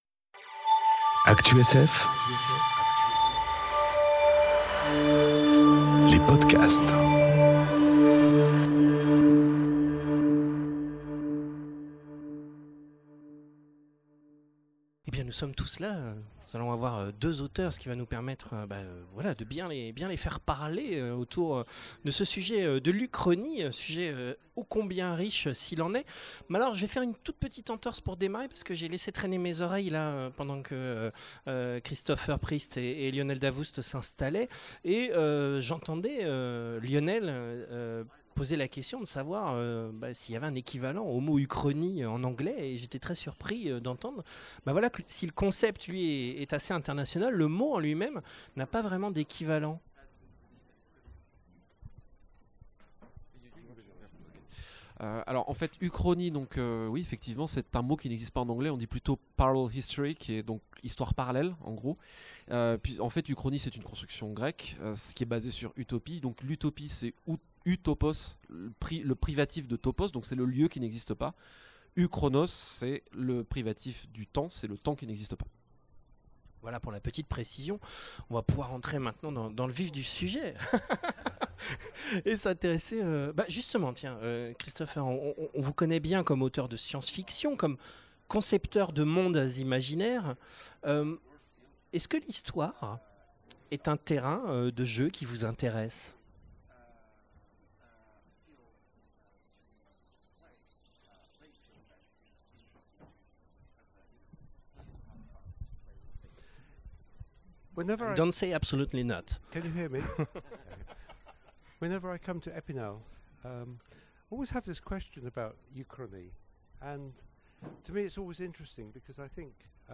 Conférence Uchronies... Et autres mondes parallèles enregistrée aux Imaginales 2018